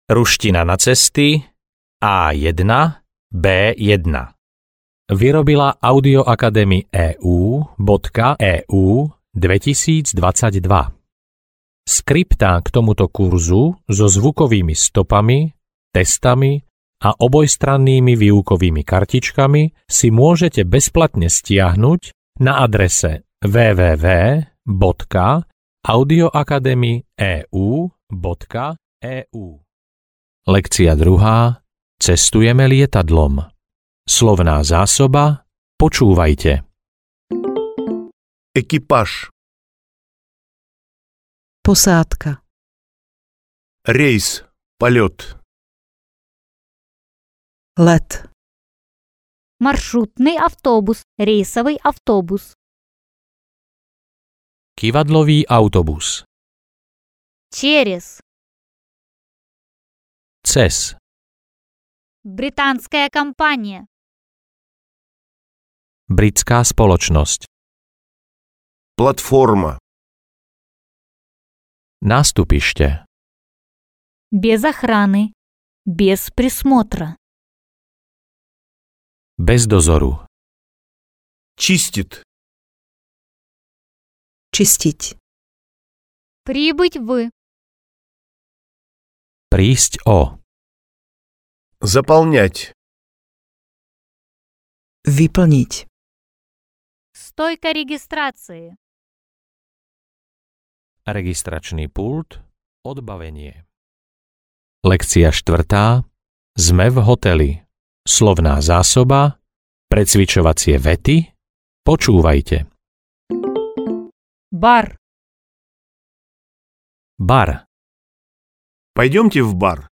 Ruština na cesty A1-B1 audiokniha
Ukázka z knihy